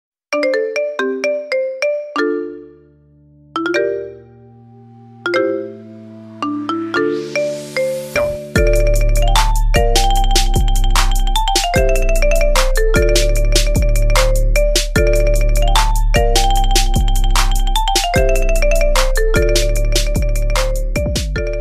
اشهر رنات ايفون mp3